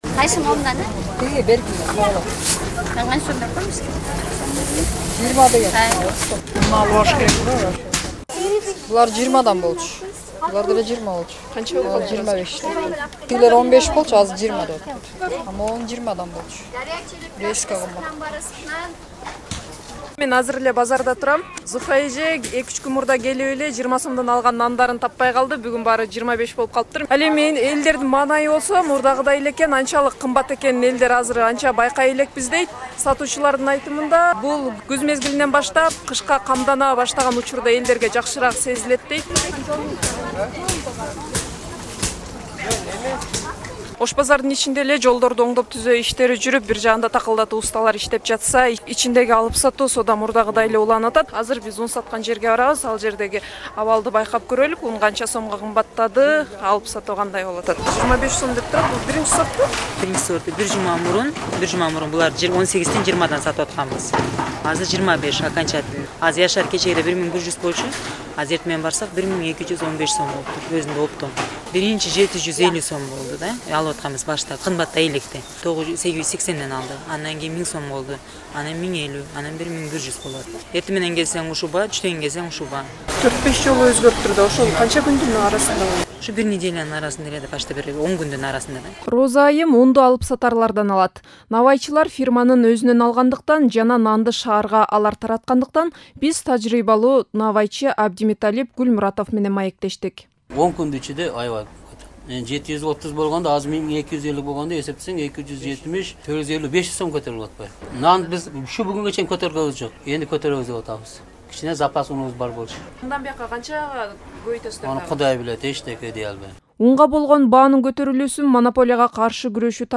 Ундун кымбатташы тууралуу репортаж